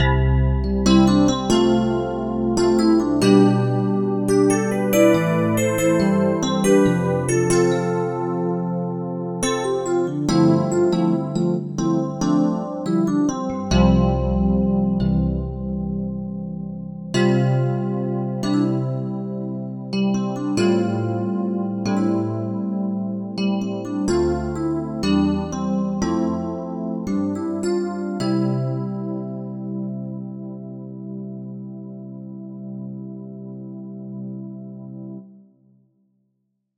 JD800 Rhodes SF2 minta /Keyscape/ ;)
JD800.mp3